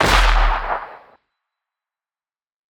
Toon explosion.wav